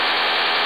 хочу знать что за сигнал